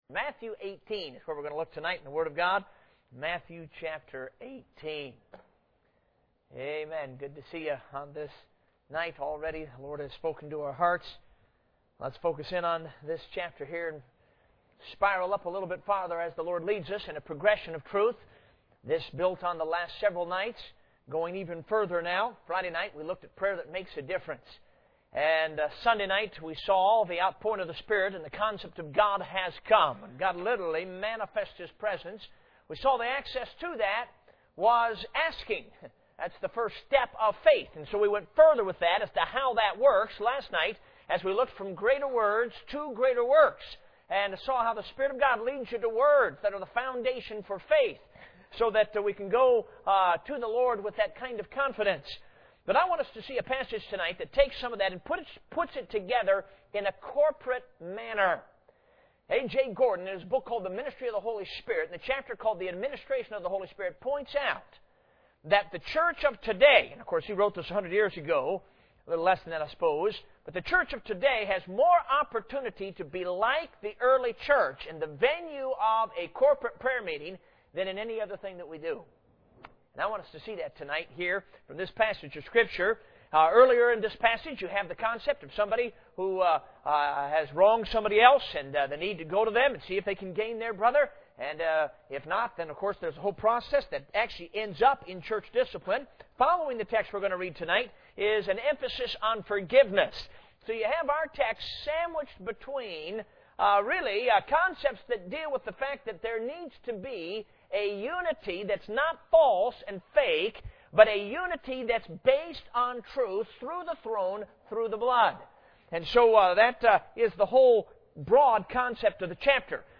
Service Type: Revival Service